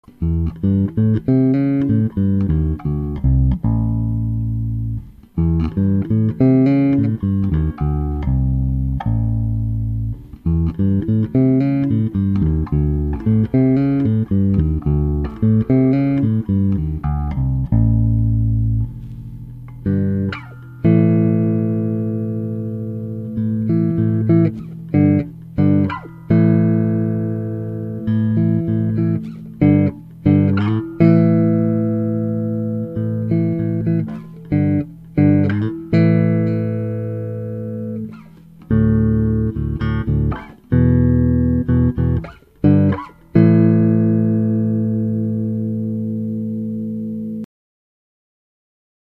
Vintage Jazz Bass sounds with a softer touch. Using Alnico II magnets makes this set slightly fatter and warmer and gives them a more dynamic response to picking.